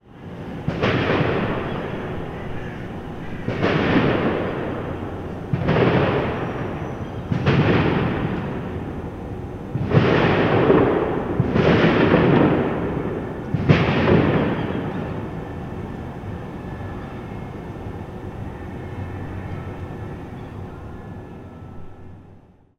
Captured these loud booms at 20:55 from the outside mics, no idea if it's weapon discharge or what. It's definitely not fireworks though, which is at least something usual around here.